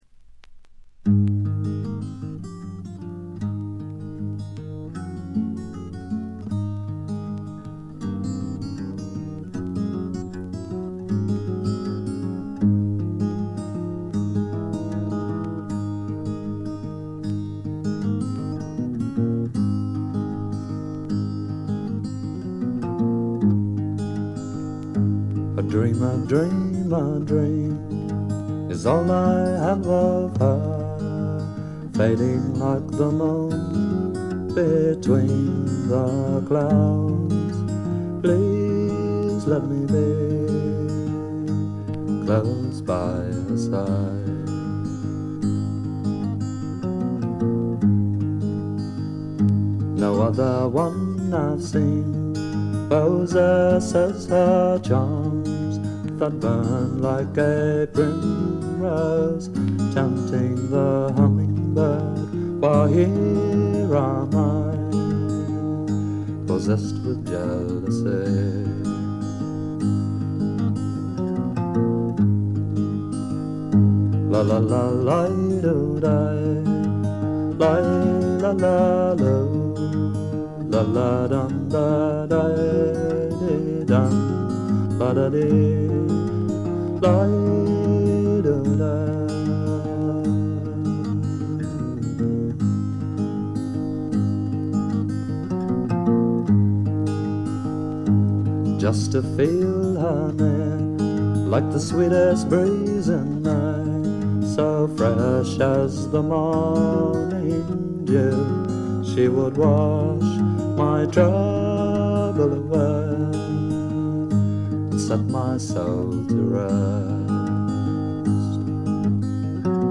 軽微なバックグラウンドノイズ、チリプチ少し。
ゲストミュージシャンは一切使わずに、自作とトラッドを味のあるヴォーカルと素晴らしいギターで表情豊かに聴かせてくれます。
試聴曲は現品からの取り込み音源です。